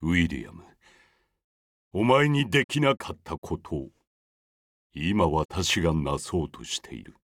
CV ：中田让治